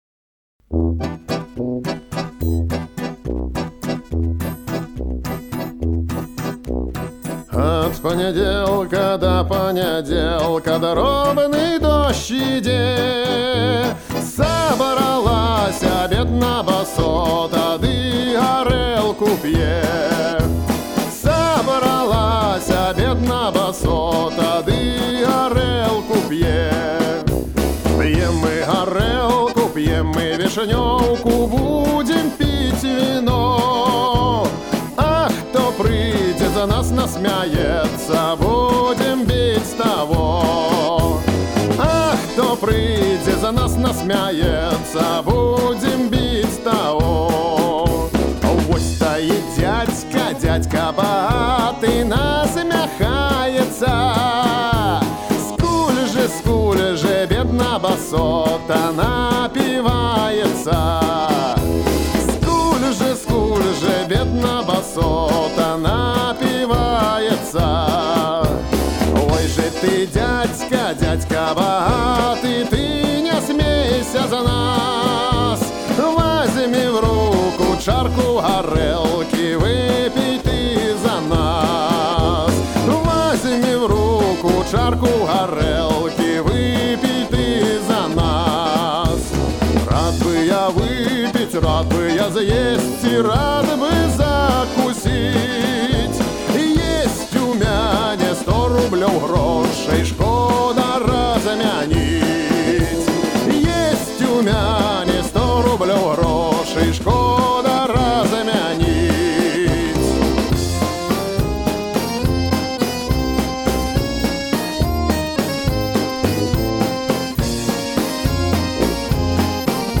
Альтернативная музыка Фолк Рок